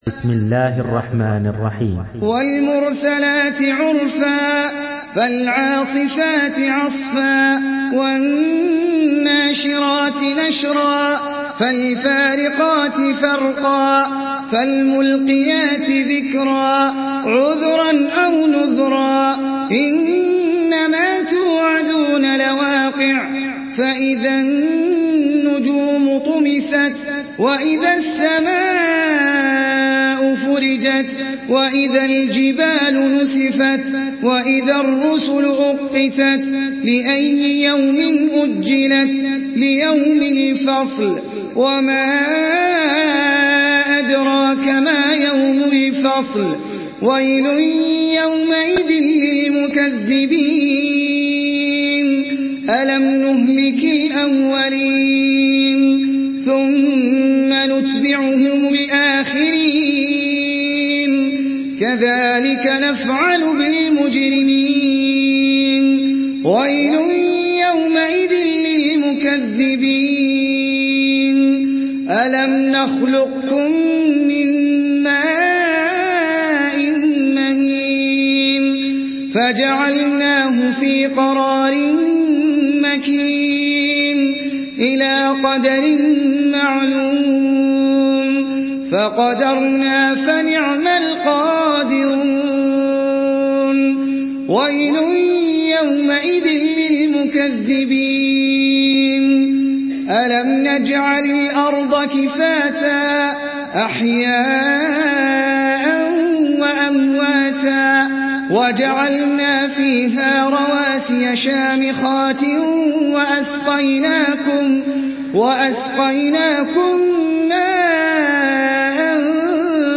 Surah আল-মুরসালাত MP3 by Ahmed Al Ajmi in Hafs An Asim narration.
Murattal Hafs An Asim